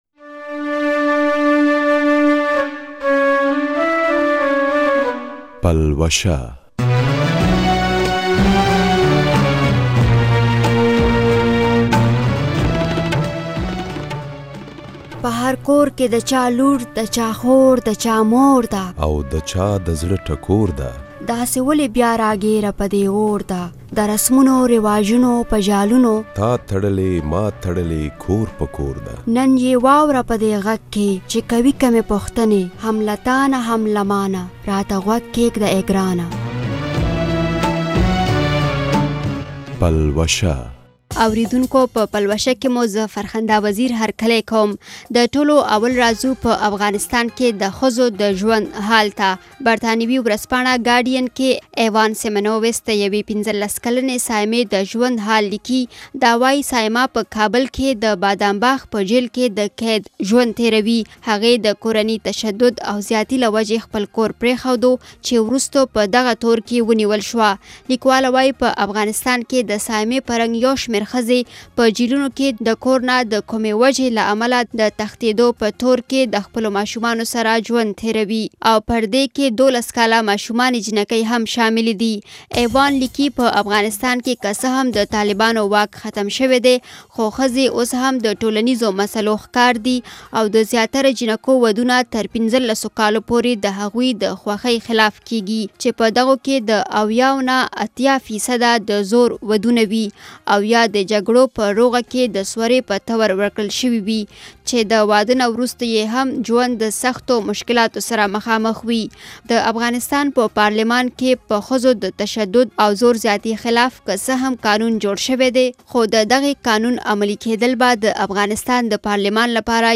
د دې اونۍ په پلوشه کې به واورۍ د پښتو موسیقۍ و تلې سندرغاړې باچازرین جان مرکه، چې وایی دومره موده یی سندرې وویلی، خو نه یی خپل کور شته، او نه دومره وس لري، چې د خپلې ناروغې علاج وکړي. ددې تر څنګ یو شمیر نور رپوټونه هم ددې اونۍ په پلوشه کې اوریدلاۍ شۍ.